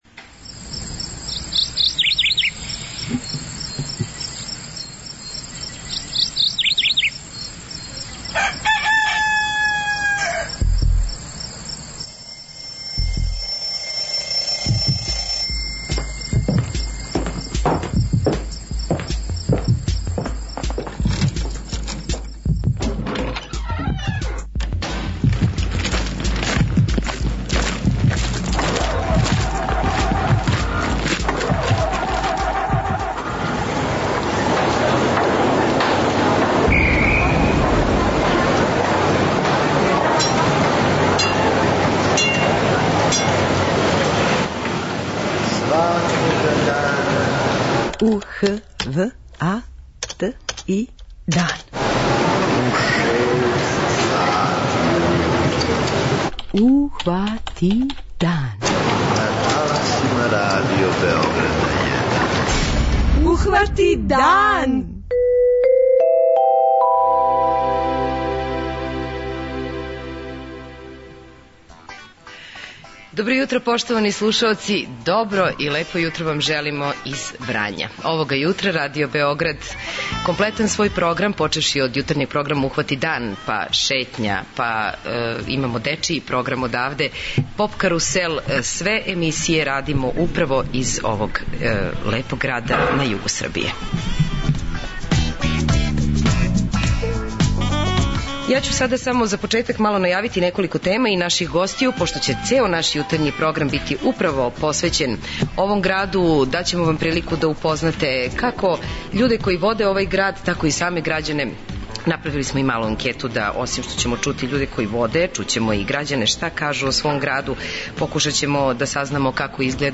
Емисију емитујемо из Врања, у оквиру акције "Радио који се види"!